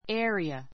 area 中 A2 é(ə)riə エ (ア)リア 名詞 ❶ （大小さまざまの） 地域 , 地方; （～用の） 場所 ; 領域 a mountain area a mountain area 山岳 さんがく 地帯 a picnic area a picnic area ピクニック用の場所 a free parking area a free parking area 無料駐車 ちゅうしゃ 区域 ❷ 面積